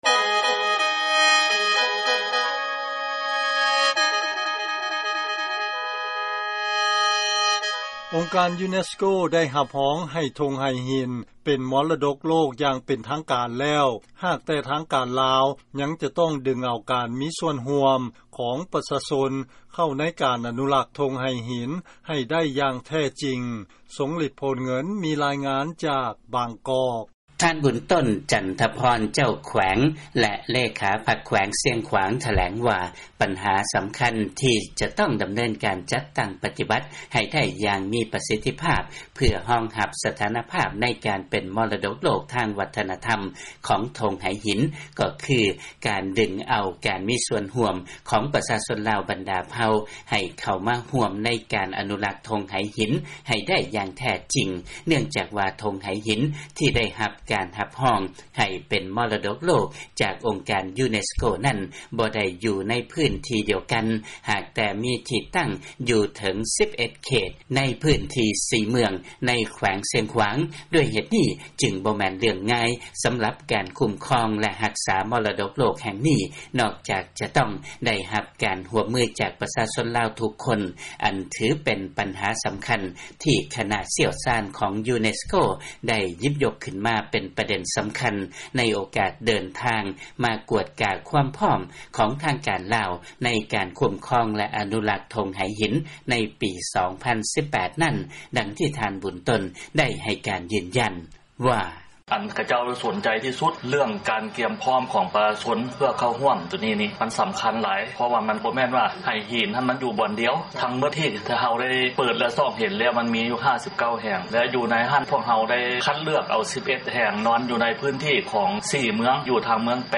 ເຊີນຟັງລາຍງານ ອົງການ UNESCO ໄດ້ຮັບຮອງ ໃຫ້ທົ່ງໄຫຫີນ ເປັນມໍລະດົກໂລກ ຢ່າງເປັນທາງການ